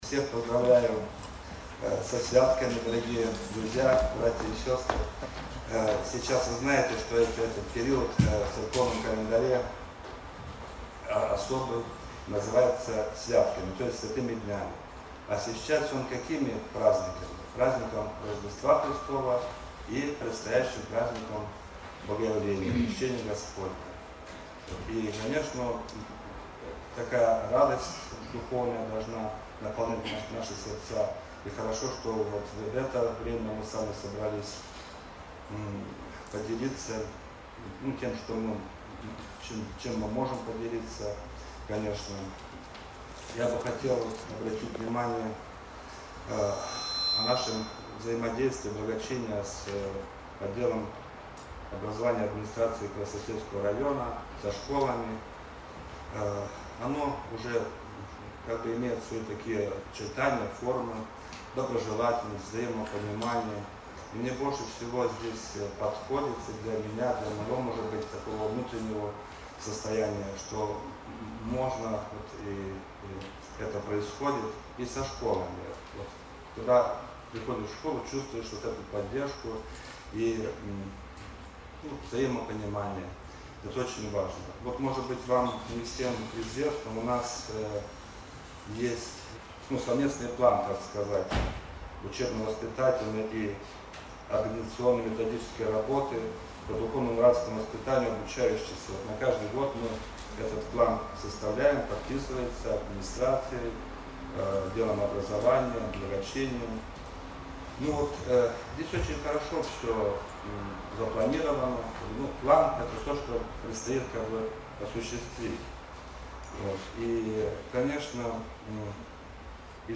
В семинаре приняли участие методисты районов города и педагоги ОРКСЭ и ОДНКНР.